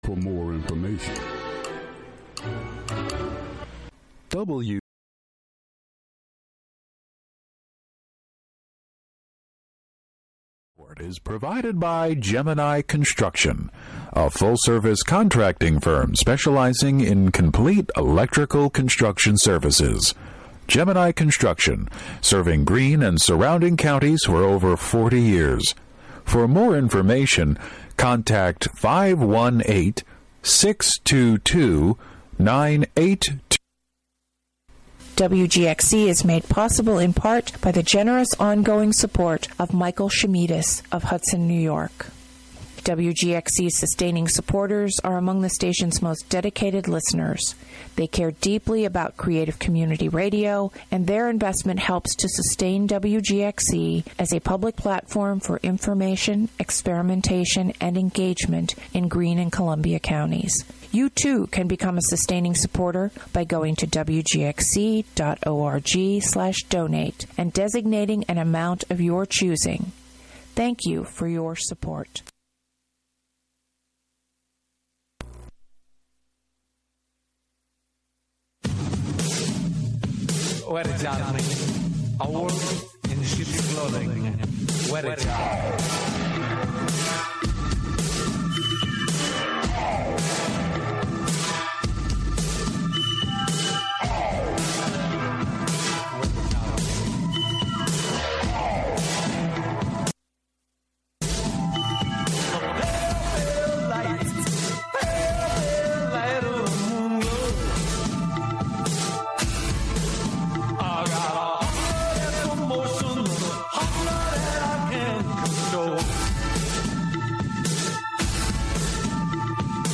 Broadcast live HiLo in Catskill.